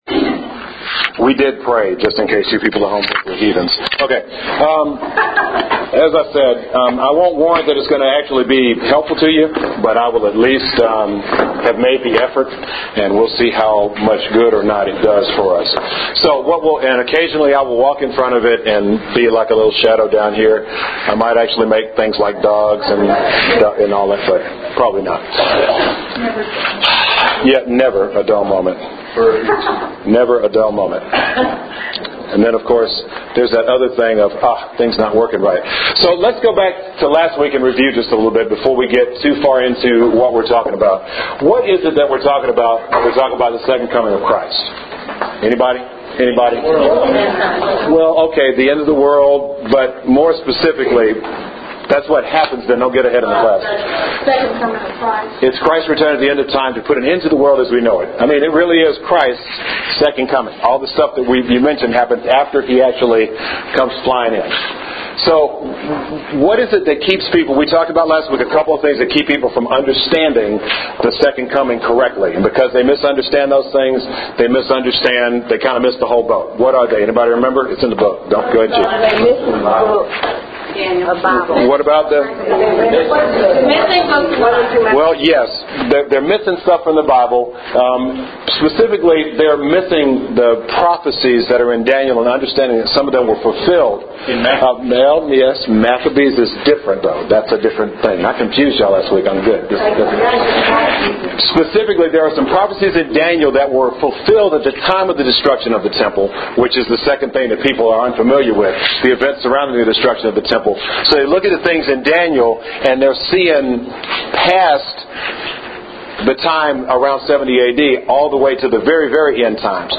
Here’s the audio file for the class; hope it’s useful.